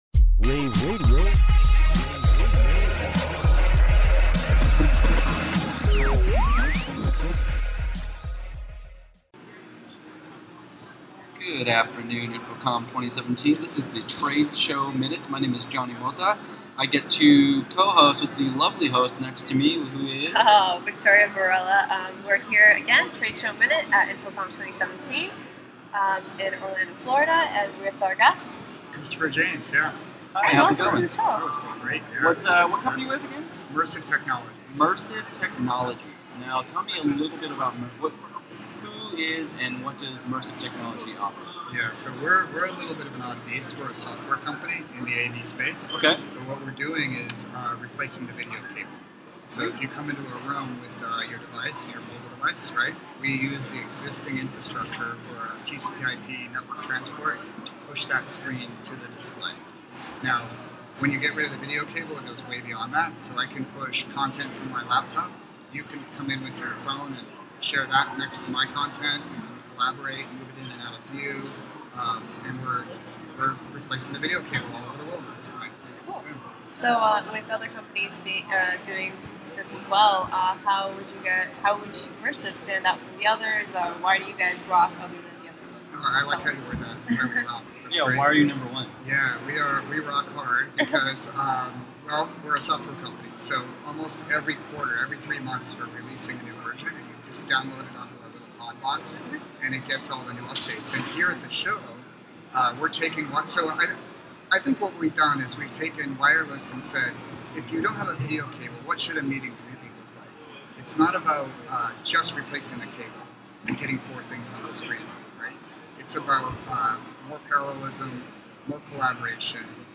here at day two of InfoComm 2107